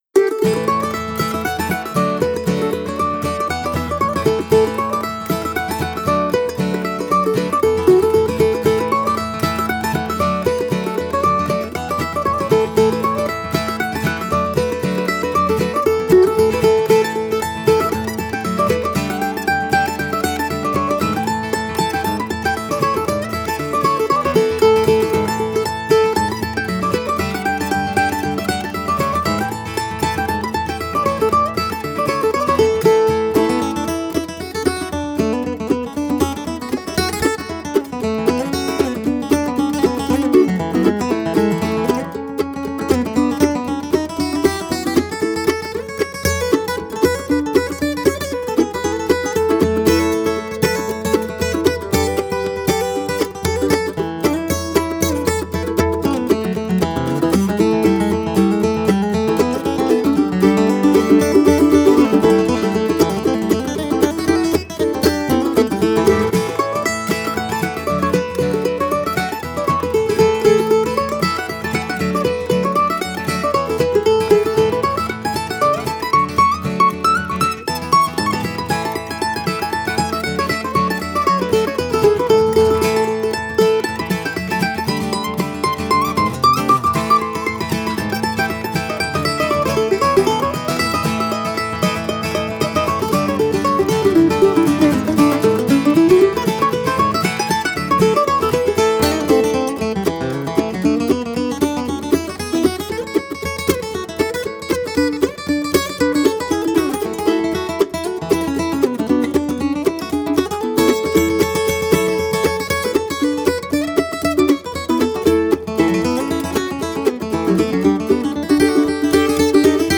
Genre: Bluegrass, Americana, Folk